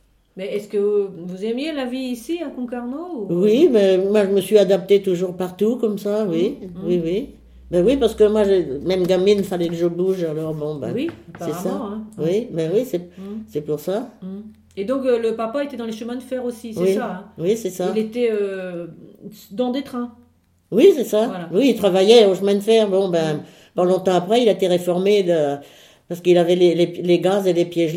Récit de vie
Catégorie Témoignage